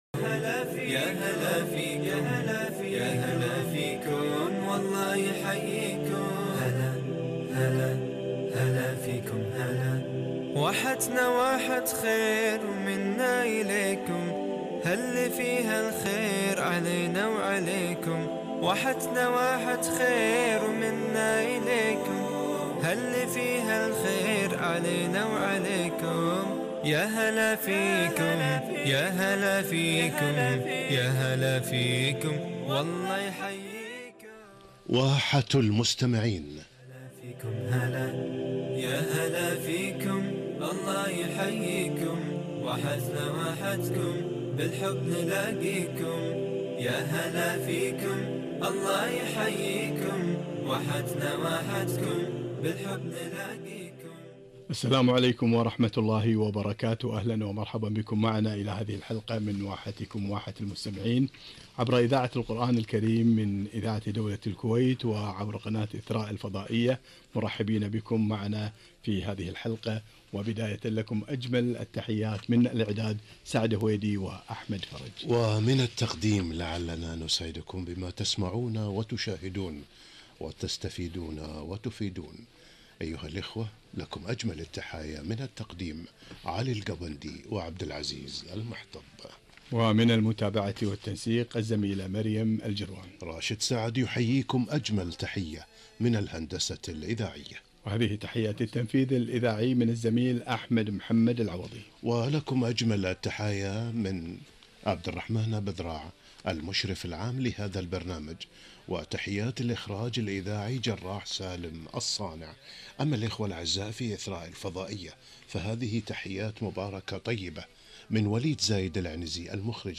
الدعاء هو العبادة - لقاء على قناء إثراء